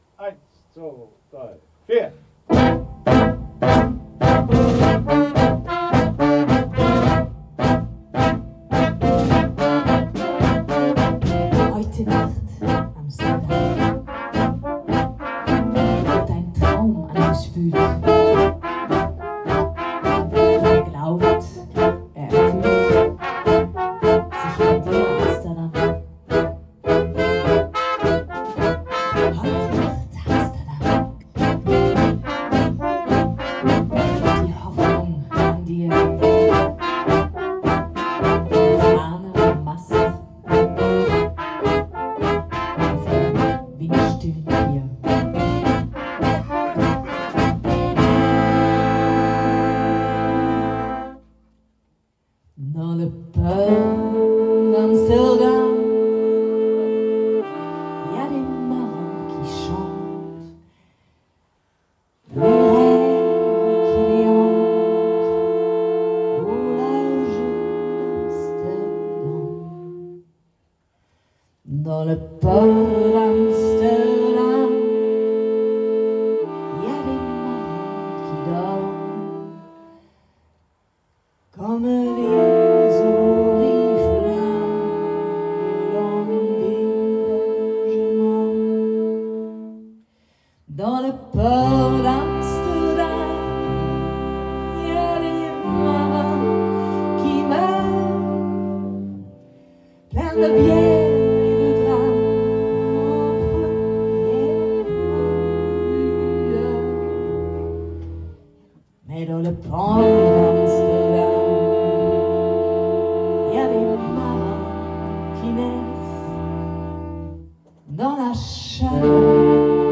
Probe